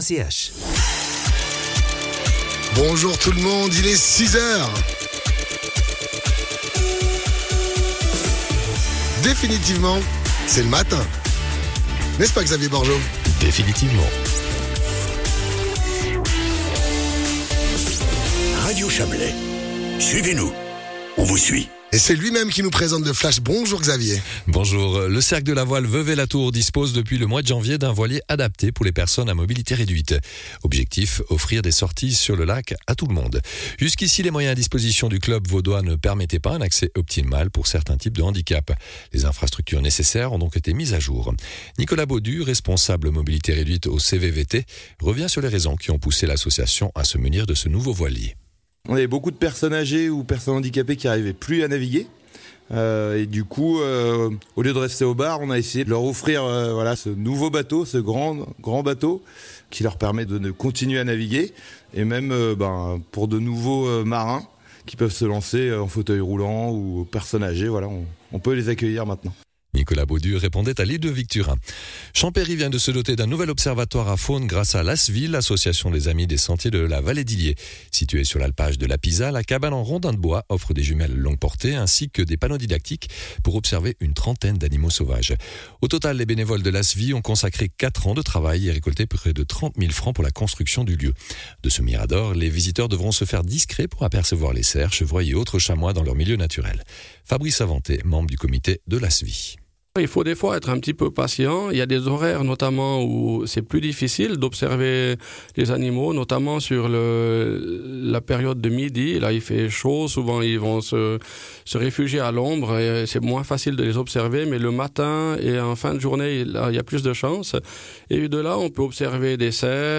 Le journal de 6h00 du 26.06.2024